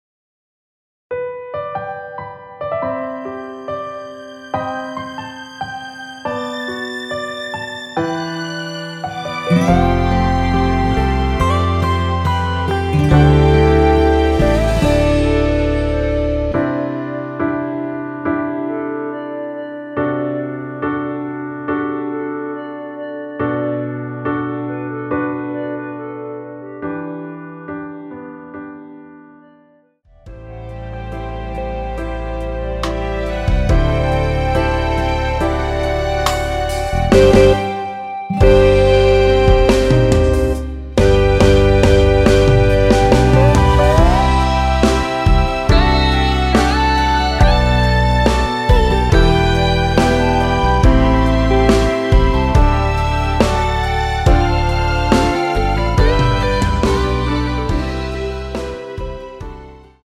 원키에서(+4)올린 멜로디 포함된 (1절앞+후렴)으로 진행되는 MR입니다.
앨범 | O.S.T
앞부분30초, 뒷부분30초씩 편집해서 올려 드리고 있습니다.